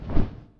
挥动zth070521.wav
通用动作/01人物/03武术动作类/挥动zth070521.wav
• 声道 單聲道 (1ch)